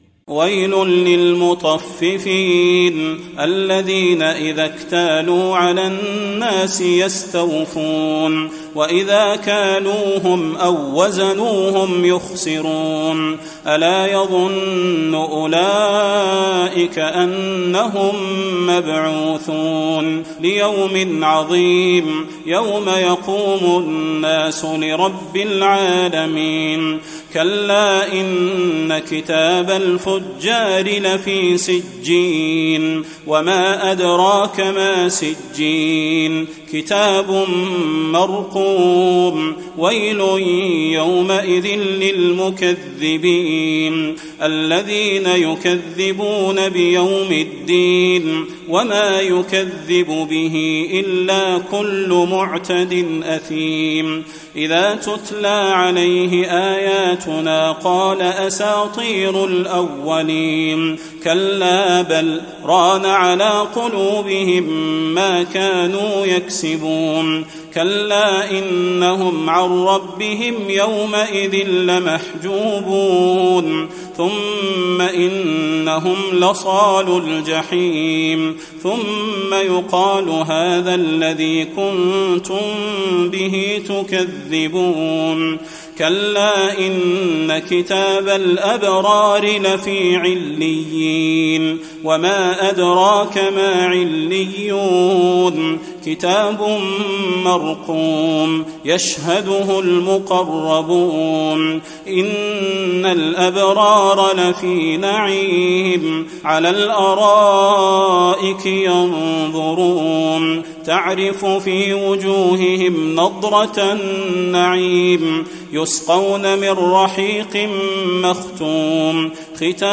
سورة المطففين | رمضان 1435هـ > السور المكتملة للشيخ صلاح البدير من الحرم النبوي 🕌 > السور المكتملة 🕌 > المزيد - تلاوات الحرمين